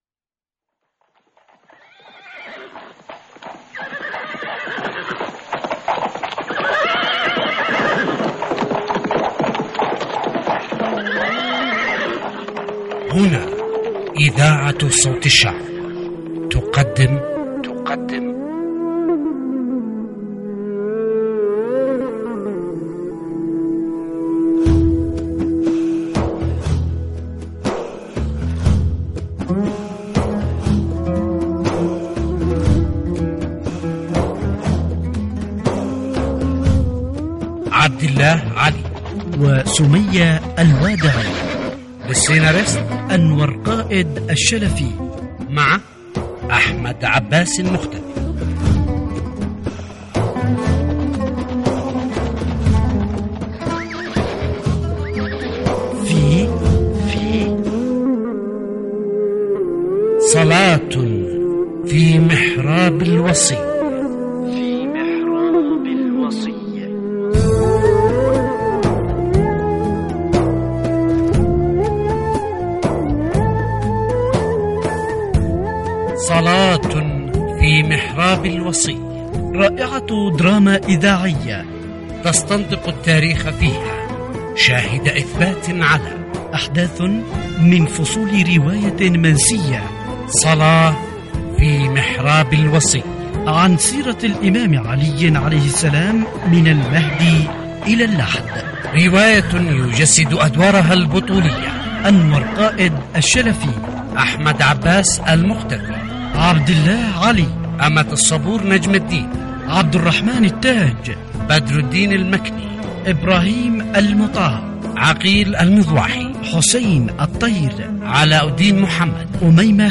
تمثيل / طاقم الاذاعة